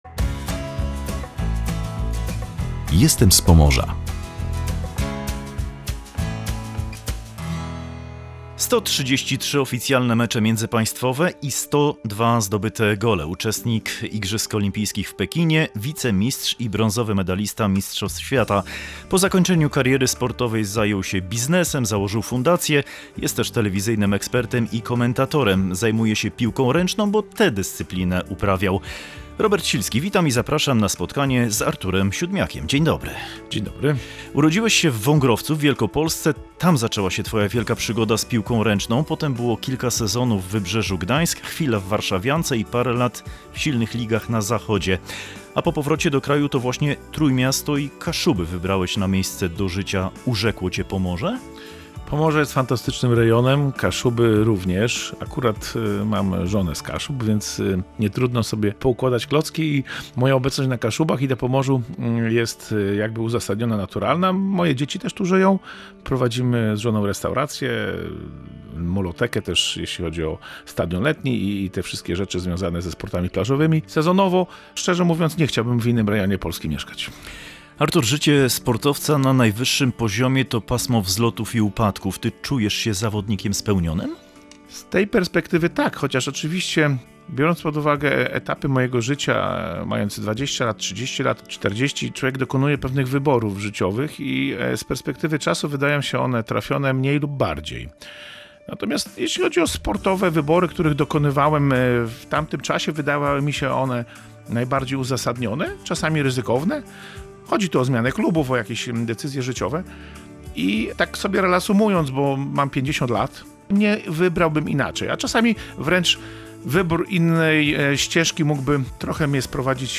Gościem audycji „Jestem z Pomorza” był Artur Siódmiak.